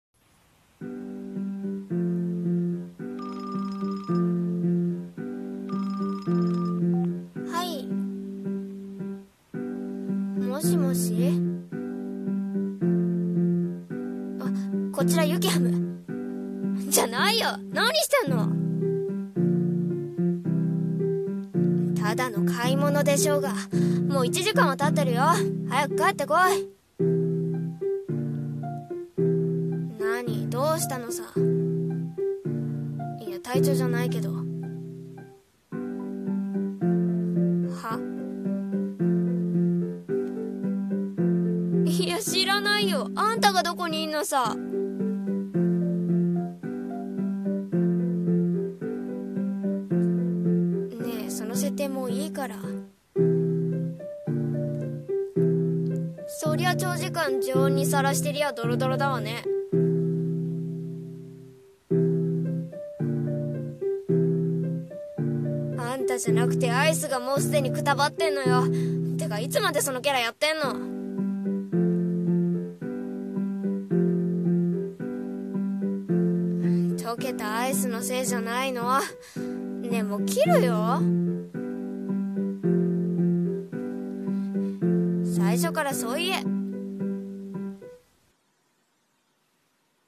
【ギャグ声劇台本】方向音痴